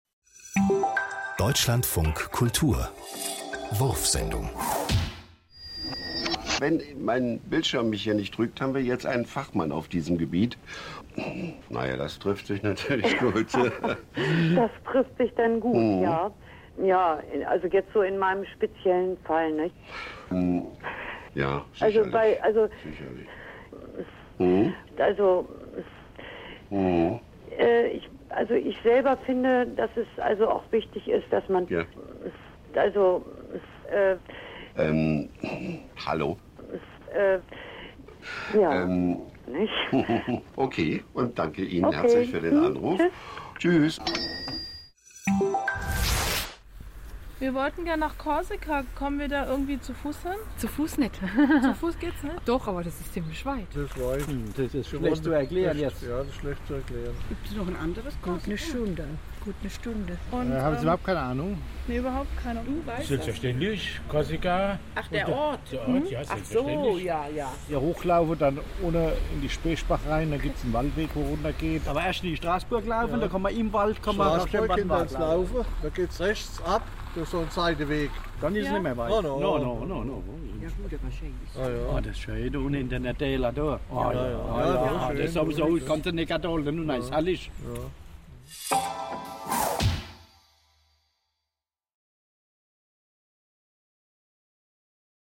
Die Wurfsendung ist das kürzeste Hörspielformat der Welt. Sie wirbt für abwegige Gedanken, neue Wahrnehmungen und intelligenten Humor.